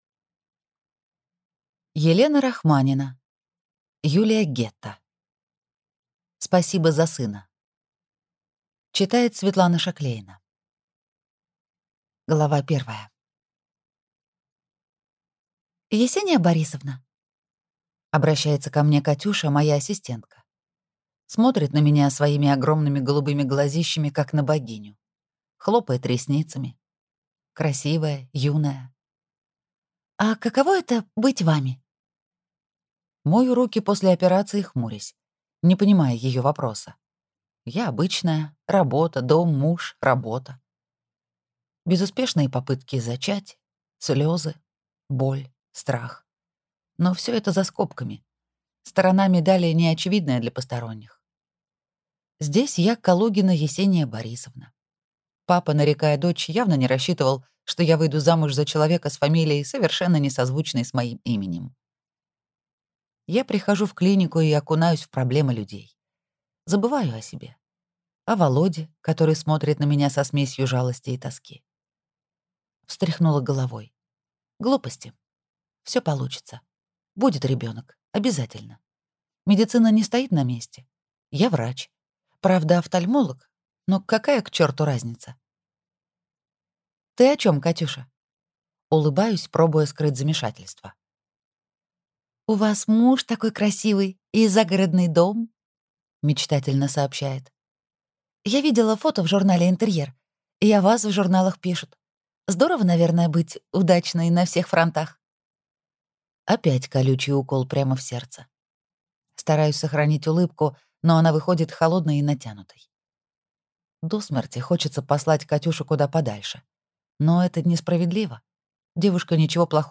Аудиокнига Спасибо за сына | Библиотека аудиокниг